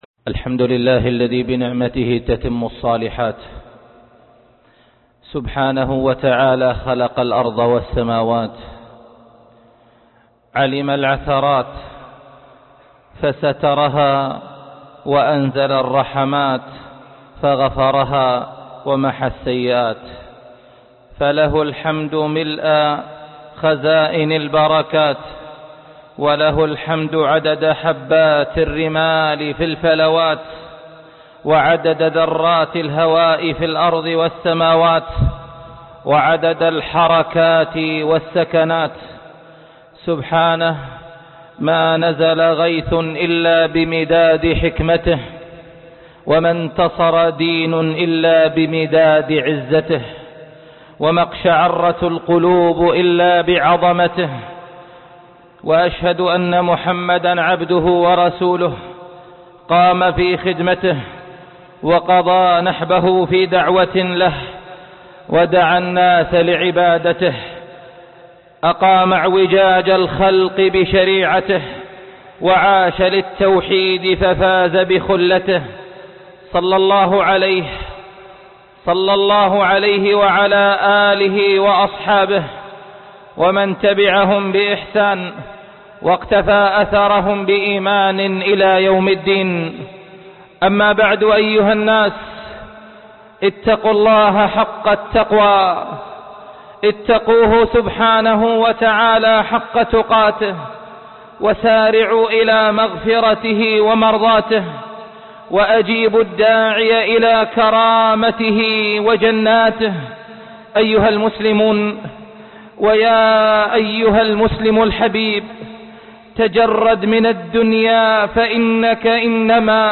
تقارب الزمان (خطبة الجمعة